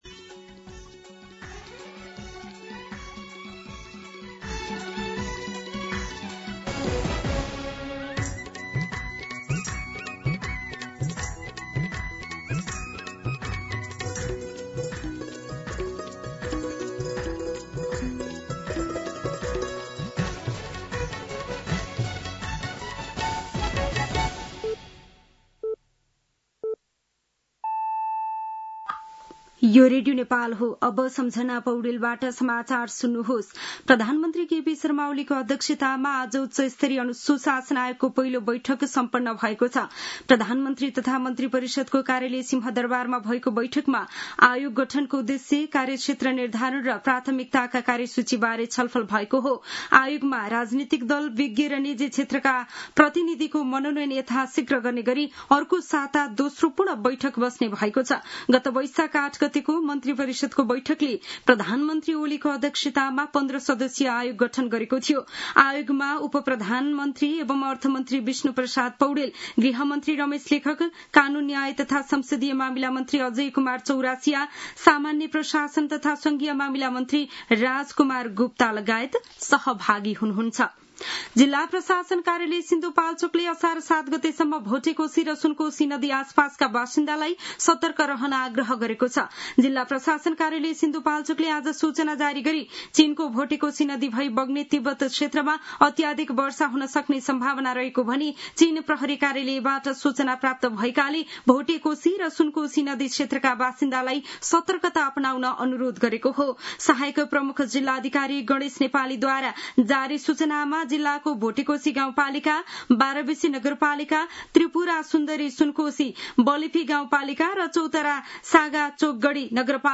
दिउँसो ४ बजेको नेपाली समाचार : ४ असार , २०८२